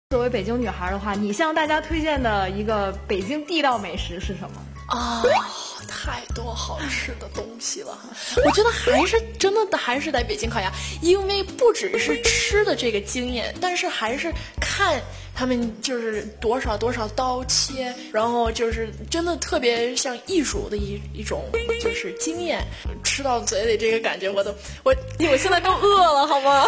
有记者请她推荐一道北京地道美食
谷爱凌兴奋回答：